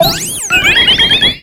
Cri de Floette Fleur Éternelle dans Pokémon X et Y.
Cri_0670_Éternelle_XY.ogg